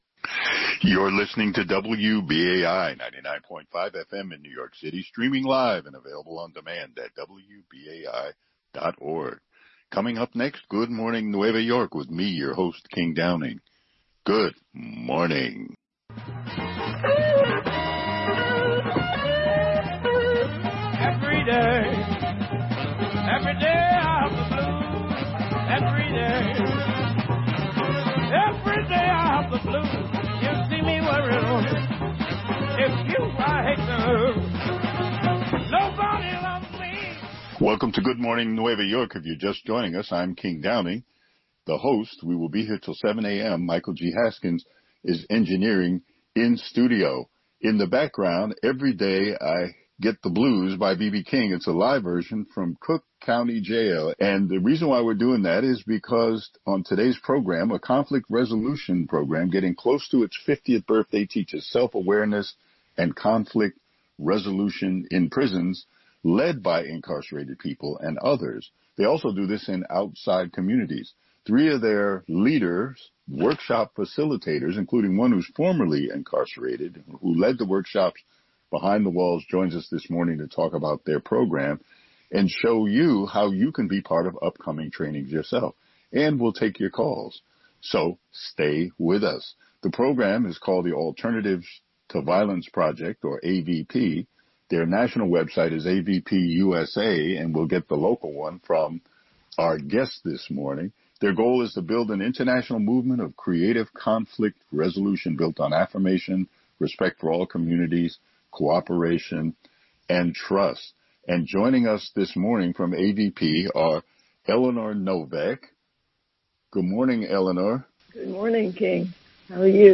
Listen to the edited interview here.